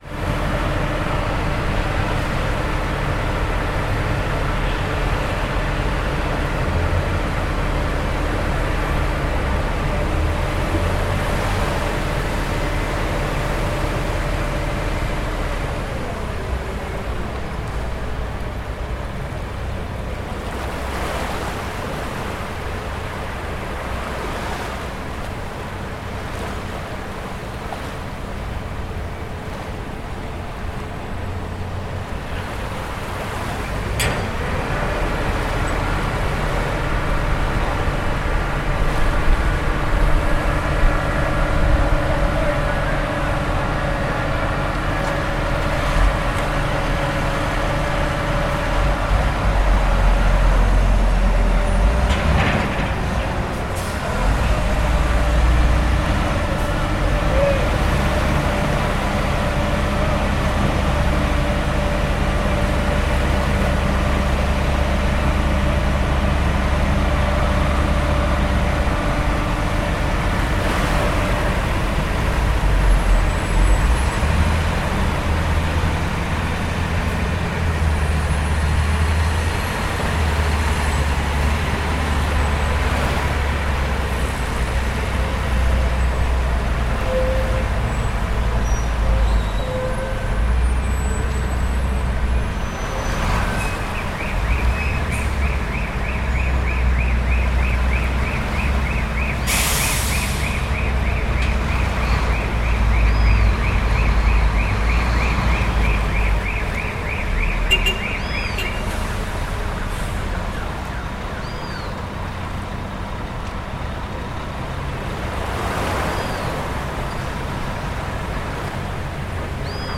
Шум подъемного автокрана